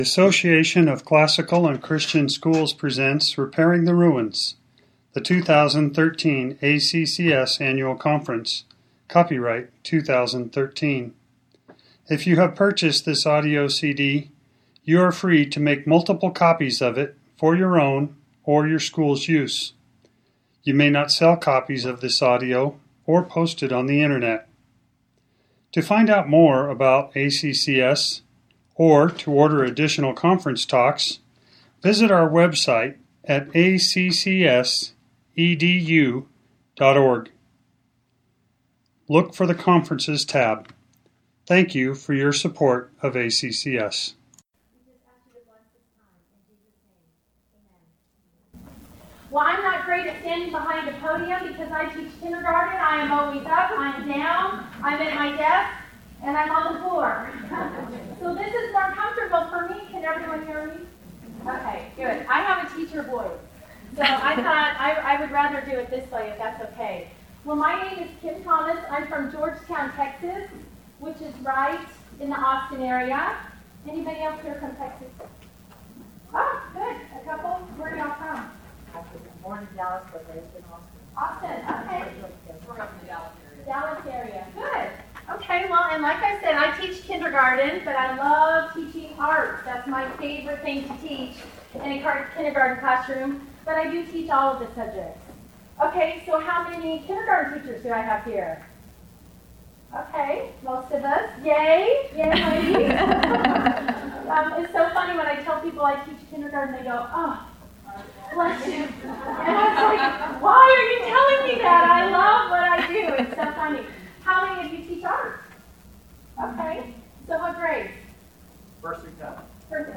2013 Workshop Talk | 1:04:08 | K-6, Art & Music